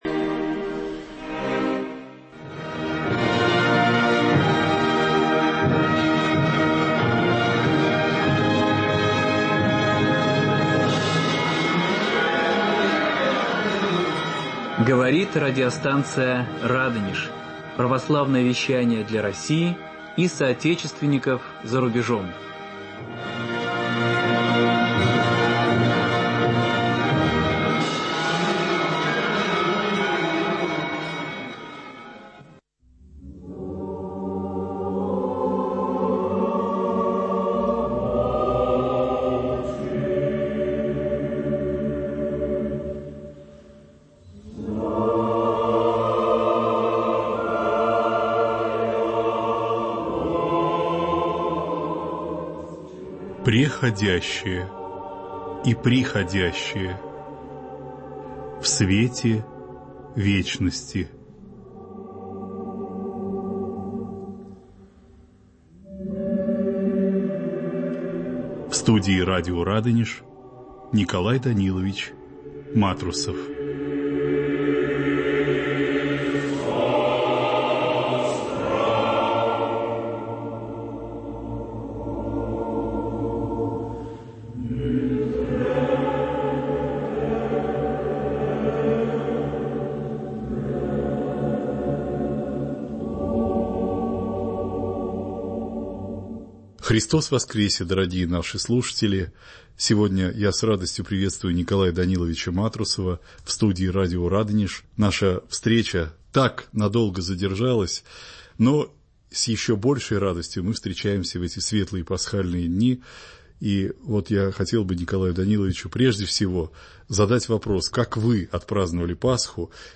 беседа с доктором экономических наук